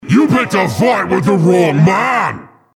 Saxton Hale audio responses